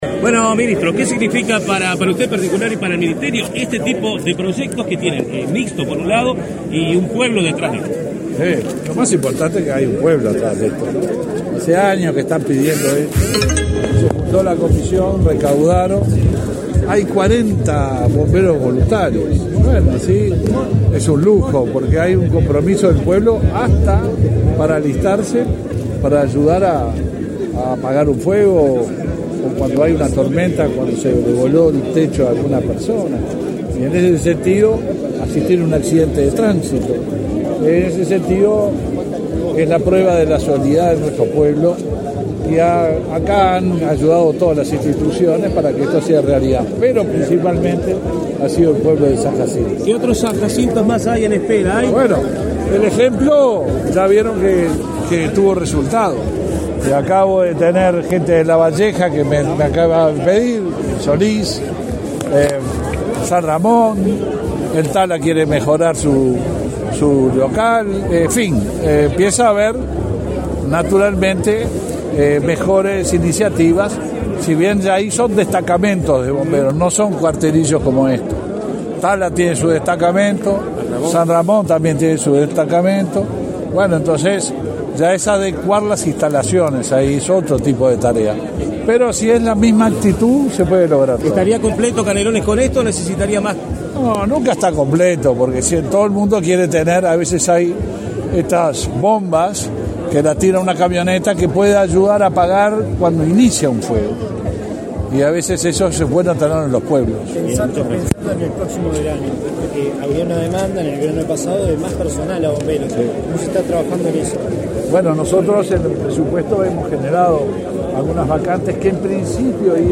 Declaraciones a la prensa del ministro del Interior, Luis Alberto Heber
Declaraciones a la prensa del ministro del Interior, Luis Alberto Heber 28/07/2023 Compartir Facebook X Copiar enlace WhatsApp LinkedIn Tras participar en la inauguración de un destacamento de bomberos compuesto por profesionales y voluntarios, en la localidad de San Jacinto, en Canelones, este 28 de julio, el titular de la cartera, Luis Alberto Heber, realizó declaraciones a la prensa.